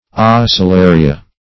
Search Result for " oscillaria" : The Collaborative International Dictionary of English v.0.48: Oscillaria \Os`cil*la"ri*a\, prop. n. [NL., fr. L. oscillare to swing.]